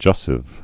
(jŭsĭv)